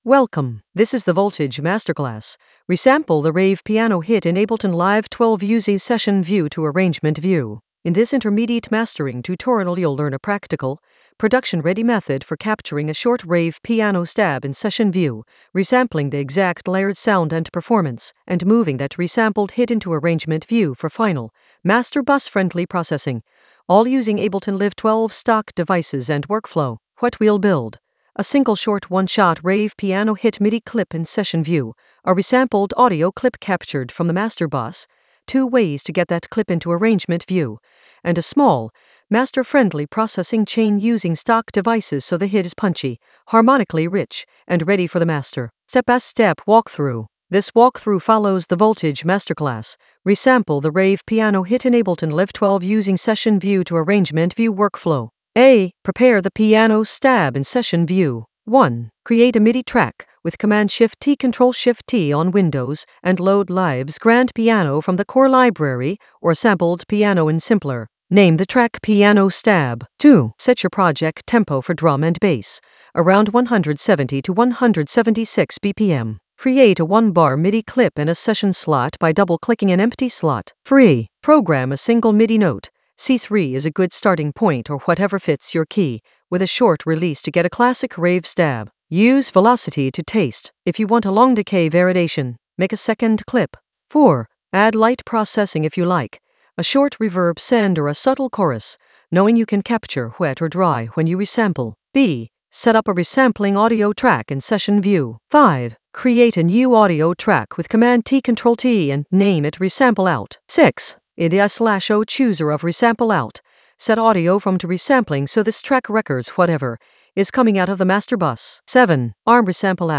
An AI-generated intermediate Ableton lesson focused on Voltage masterclass: resample the rave piano hit in Ableton Live 12 using Session View to Arrangement View in the Mastering area of drum and bass production.
Narrated lesson audio
The voice track includes the tutorial plus extra teacher commentary.